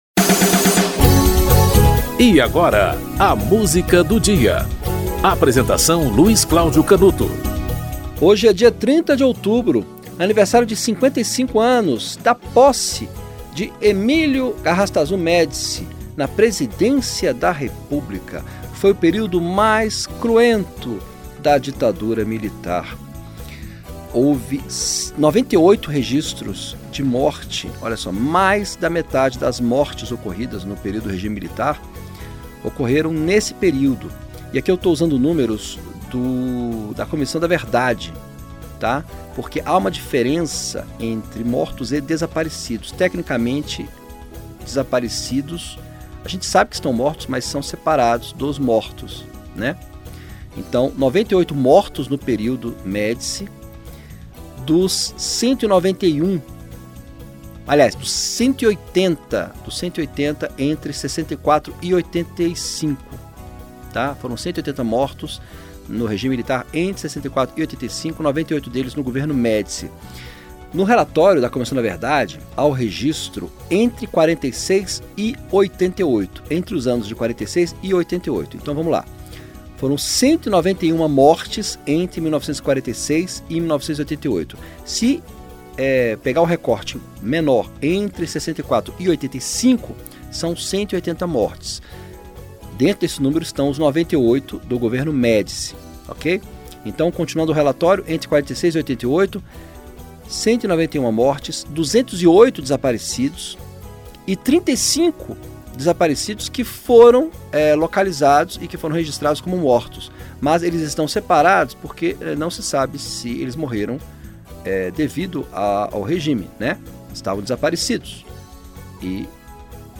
Produção e apresentação